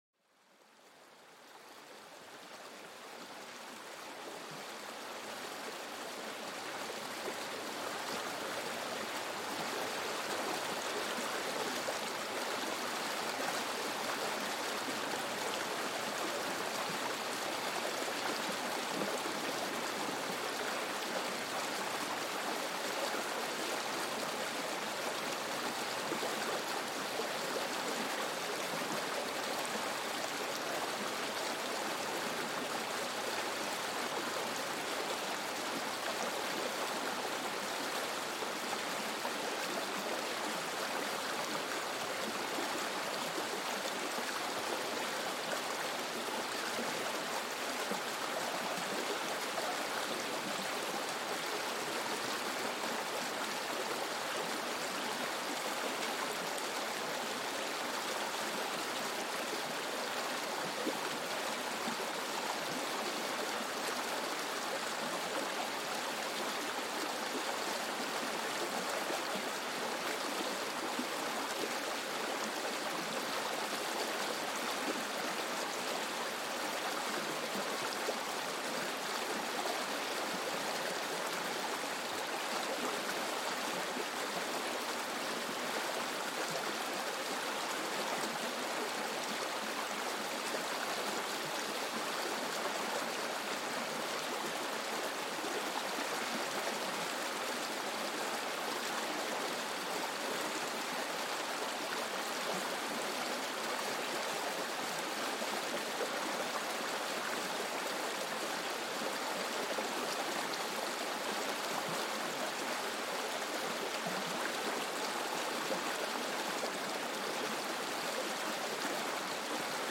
Serenidad de un río: calma tu mente con el suave flujo del agua